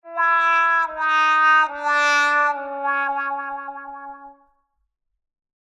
brass-fail-8.ogg